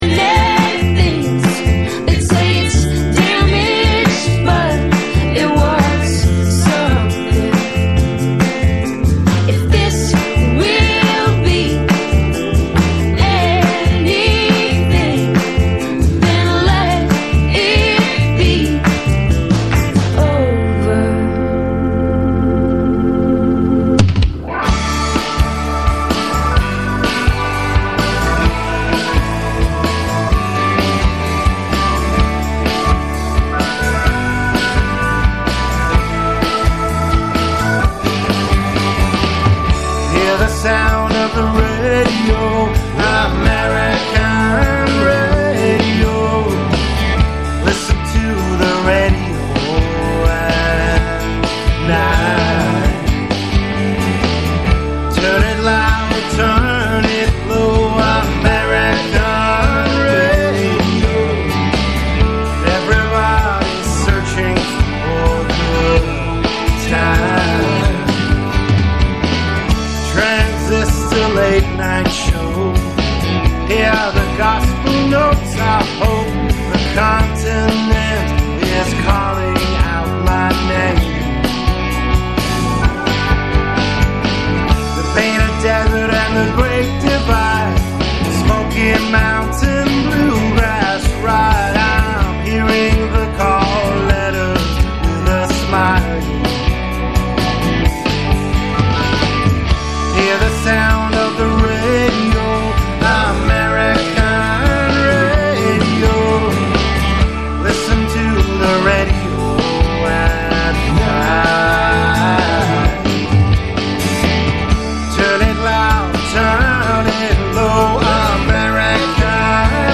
gypsy music
radio , Russian music